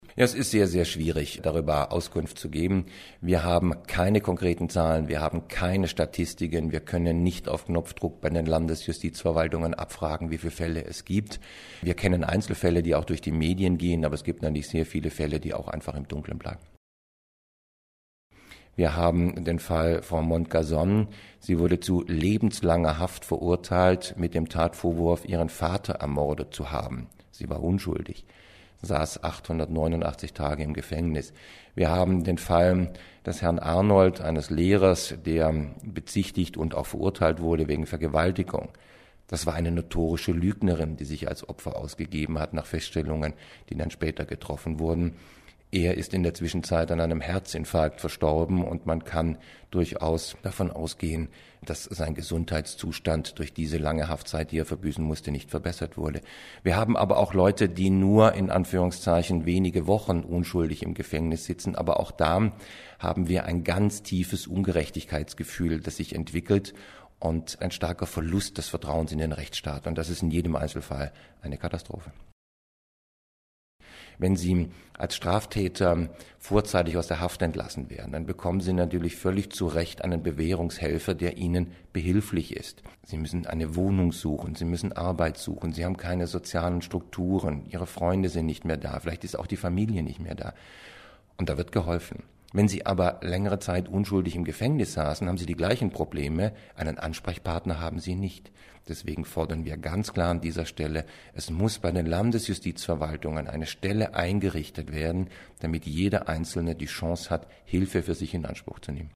Kollegengespräch: Justizirrtümer in der Diskussion